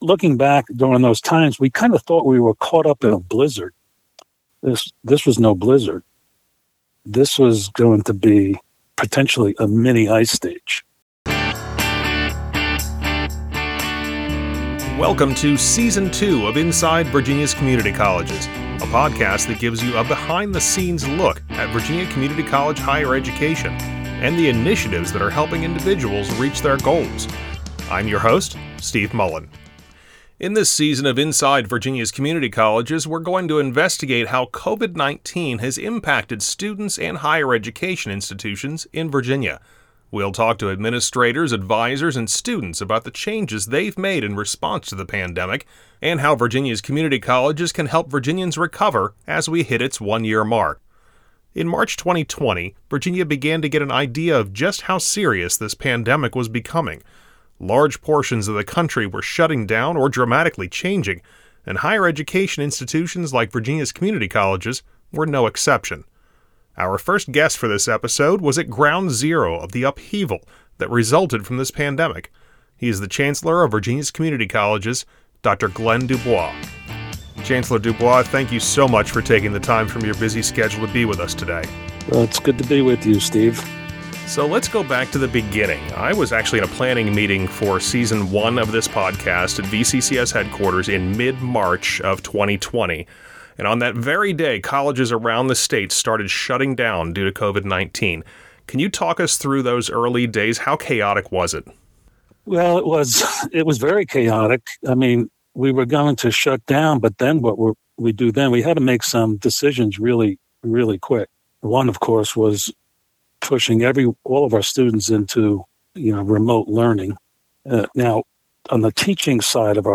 In this season of the podcast, we will investigate how COVID-19 has impacted students and higher education institutions in Virginia. We’ll talk to administrators, advisors, and students about the changes they’ve made in response to COVID-19 and how Virginia’s community colleges can help Virginians recover as we hit the one-year mark of the pandemic.
Our first guest is the chancellor of Virginia’s Community Colleges, Dr. Glenn DuBois.